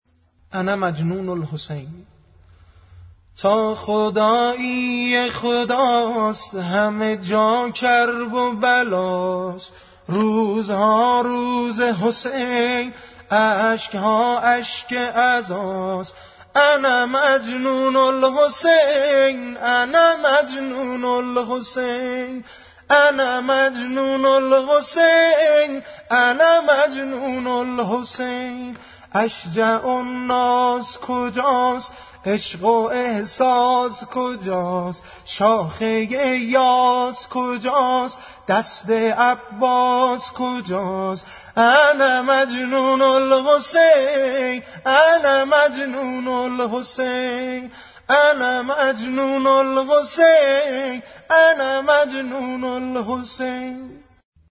صوت سبک